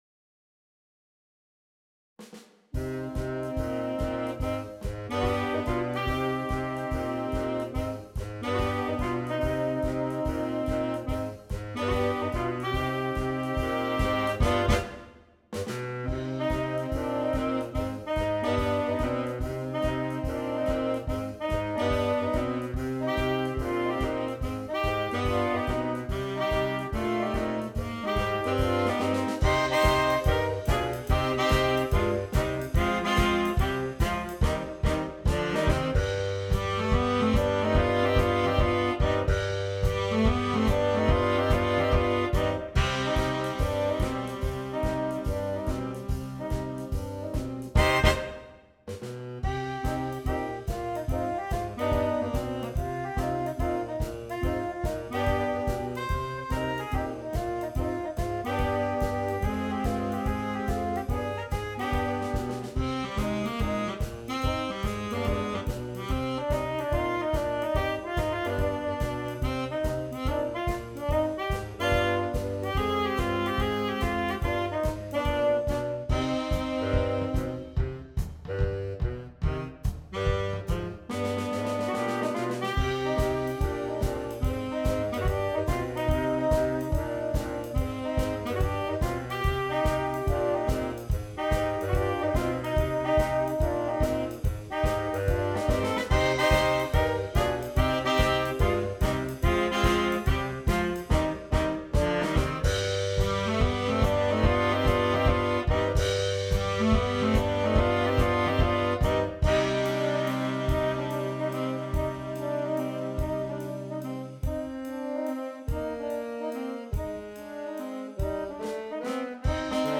• Saxophone Quartet (AATB) Optional Drums